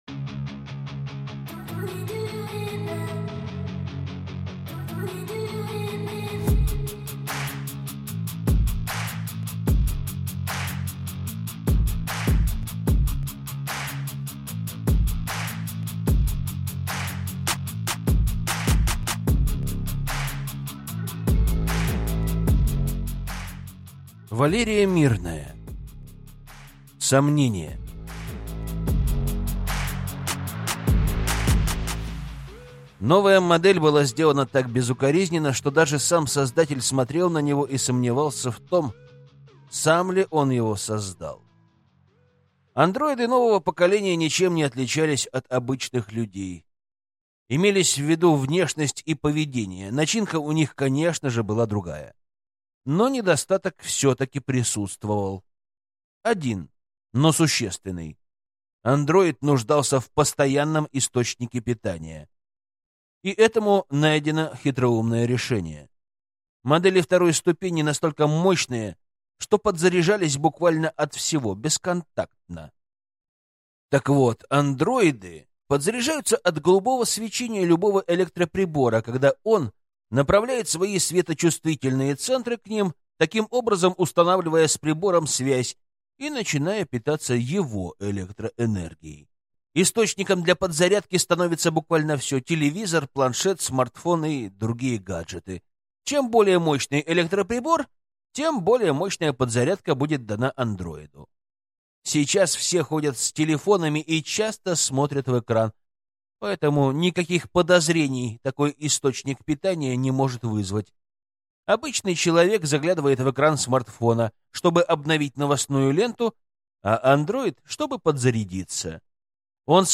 Аудиокнига Сомнение | Библиотека аудиокниг